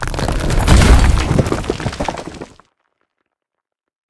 combat / megasuit / step2.ogg
step2.ogg